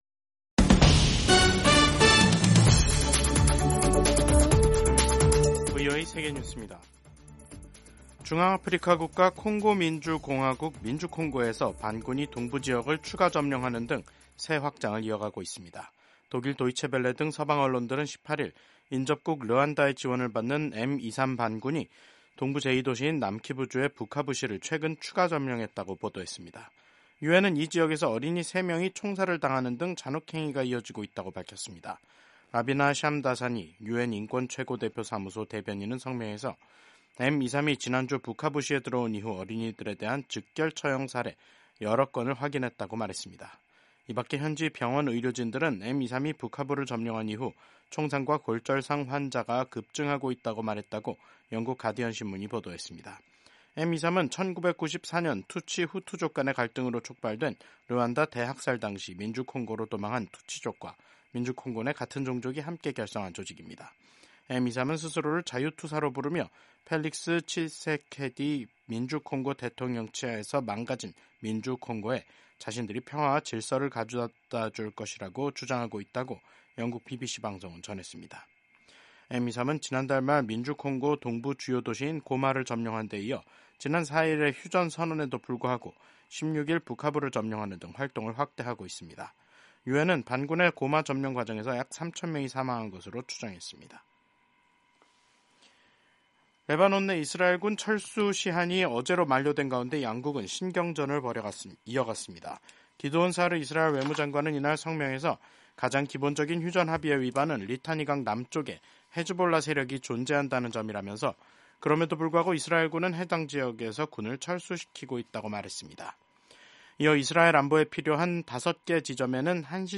세계 뉴스와 함께 미국의 모든 것을 소개하는 '생방송 여기는 워싱턴입니다', 2025년 2월 19일 저녁 방송입니다. 도널드 트럼프 미국 대통령이 이번 달 안에 블라디미르 푸틴 러시아 대통령을 만날 수도 있다고 말했습니다.